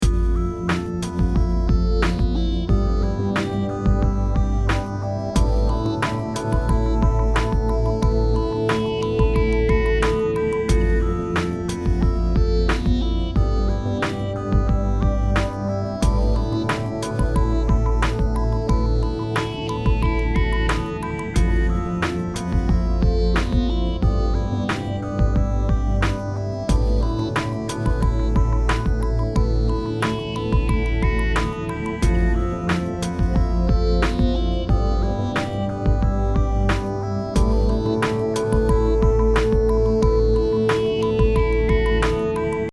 Very slow, chill electronic song.
Great menu loop, or loading screen.